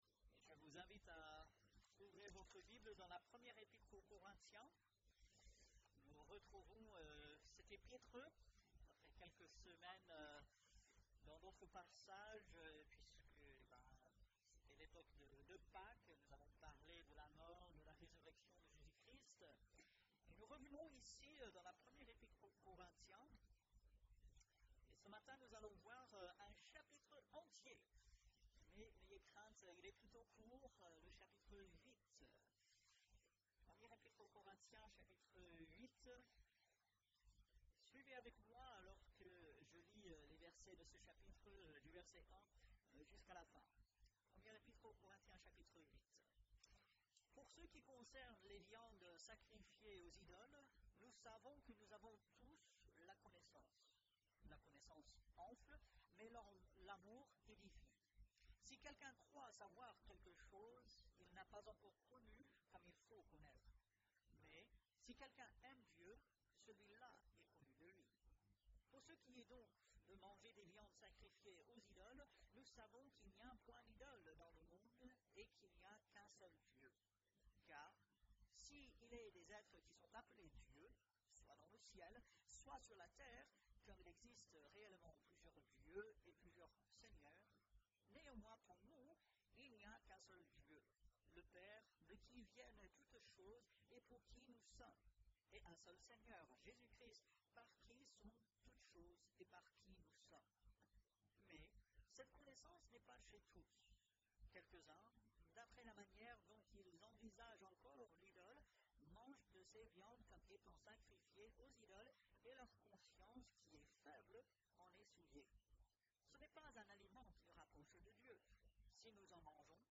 Ici nous vous proposons l'écoute des prédications qui sont apportées le dimanche matin.